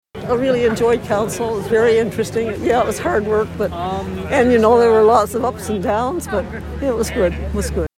A plaque by the river was unveiled and the footbridge over the river was named in her honour in 2020.  She spoke to Quinte News at the event.